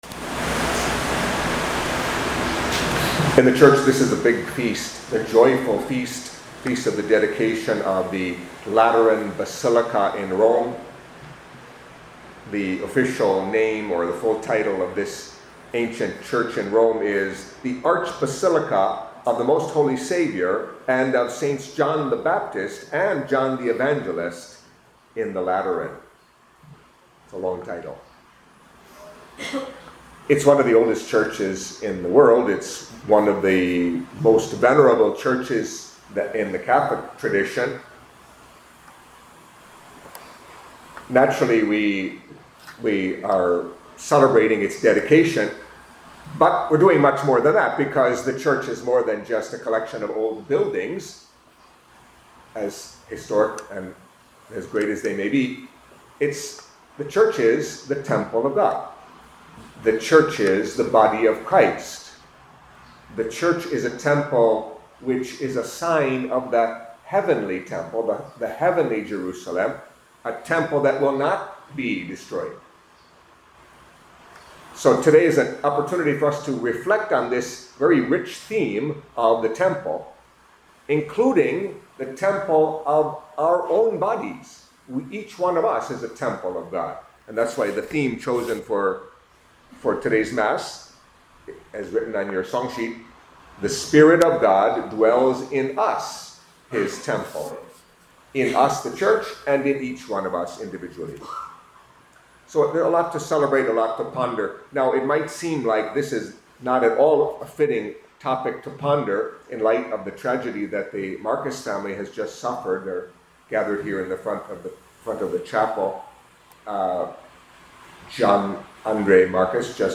Catholic Mass homily for the Feast of the Dedication of the Lateran Basilica